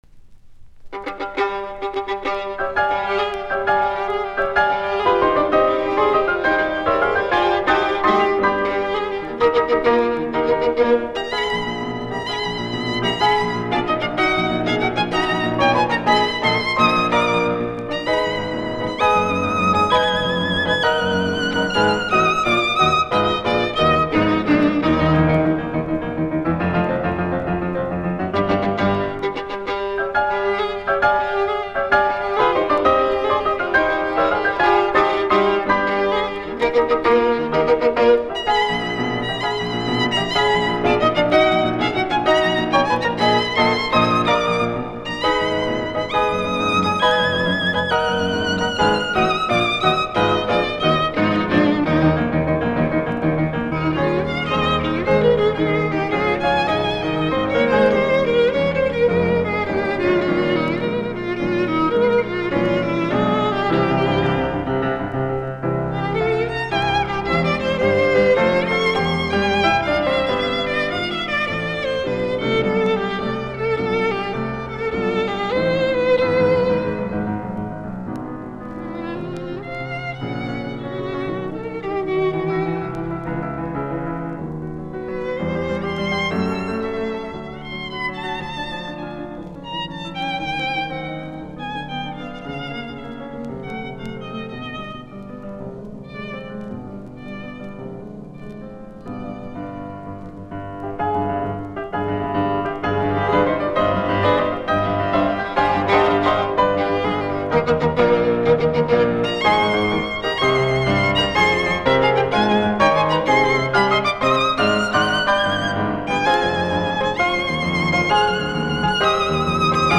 Soitinnus: Viulu, piano.